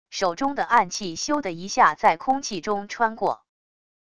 手中的暗器咻的一下在空气中穿过wav音频